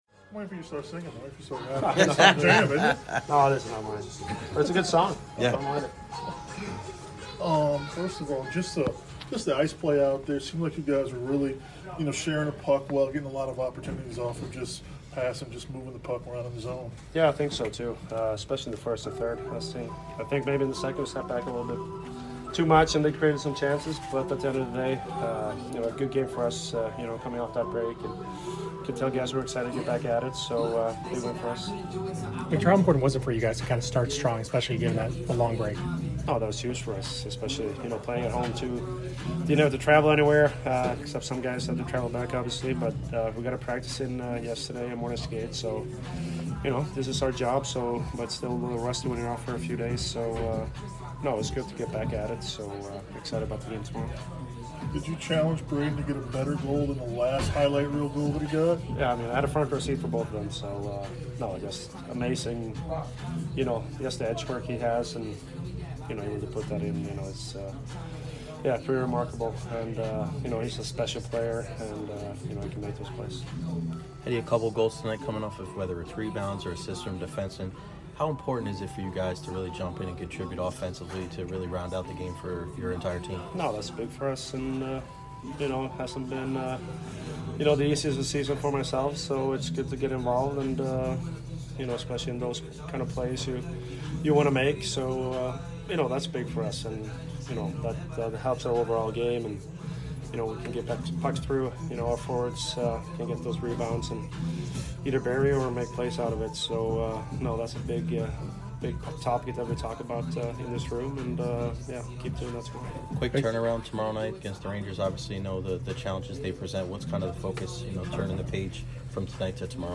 Victor Hedman Post Game 12/28/22 vs MTL